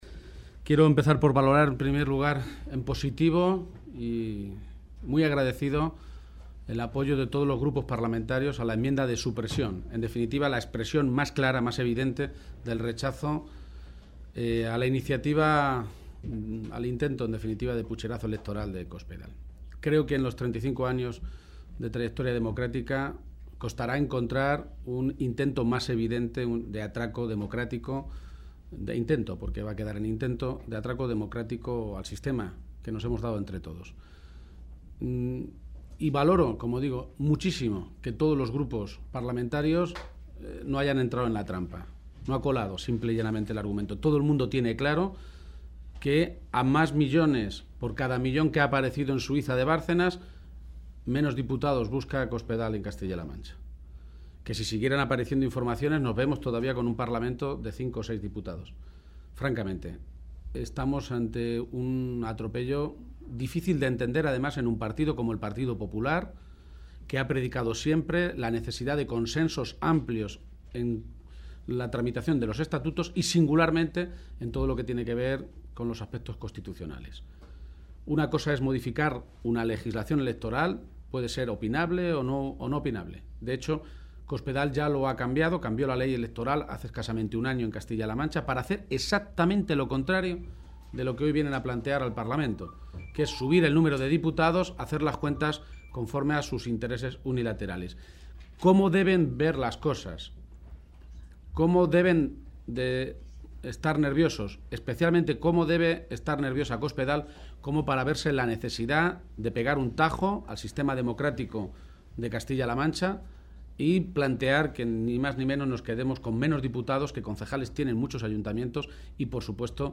García-Page se pronunciaba de esta manera esta tarde en Madrid, en el Congreso de los Diputados, tras la votación en la Comisión Constitucional del dictamen de la Ponencia y de las enmiendas a la reforma del Estatuto de Castilla-La Mancha.
Cortes de audio de la rueda de prensa